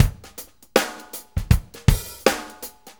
Shuffle Loop 23-03.wav